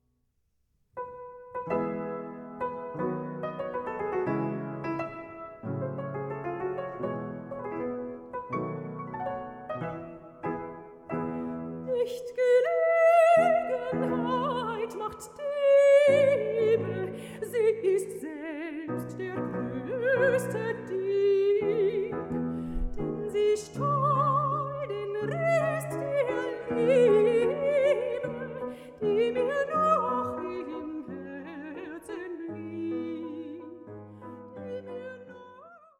Hammerflügel